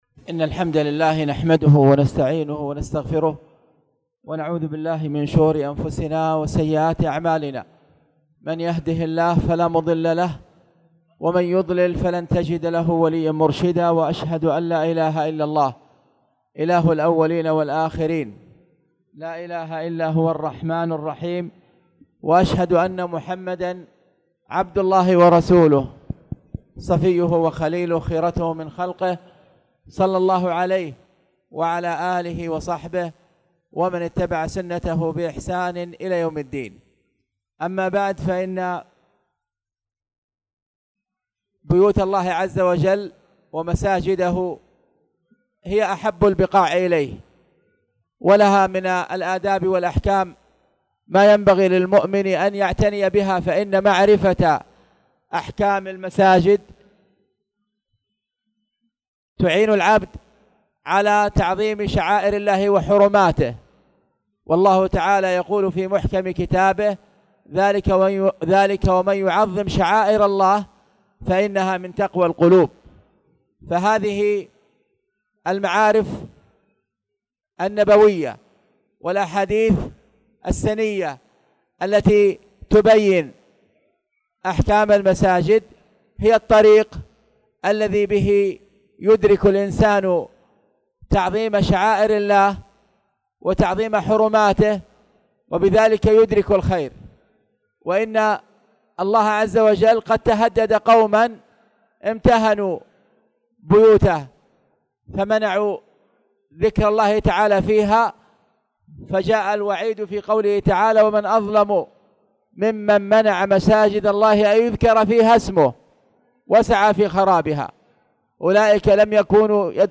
تاريخ النشر ٦ رجب ١٤٣٨ هـ المكان: المسجد الحرام الشيخ